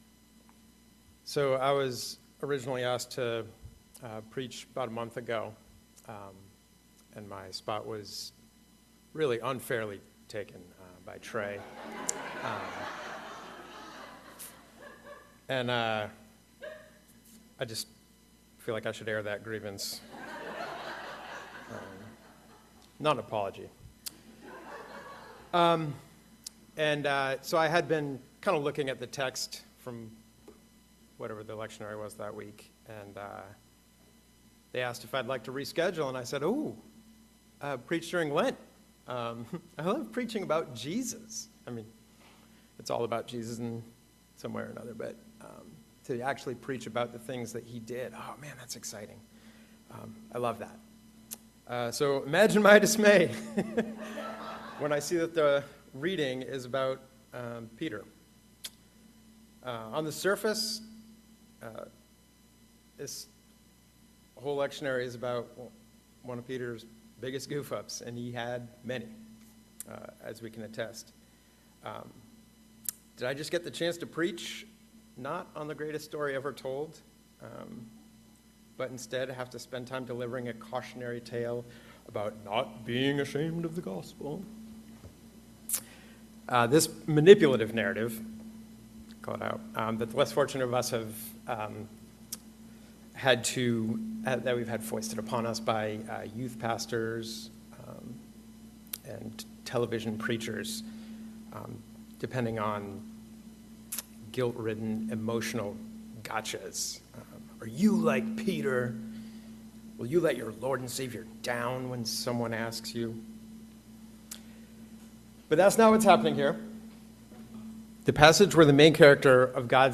the message on this third Sunday of Lent. We find Peter, the beloved disciple of Jesus, at probably one of the worst times of his life, denying he knew Jesus when he swore he wouldn’t. But this story isn’t so much about Peter as it is about us.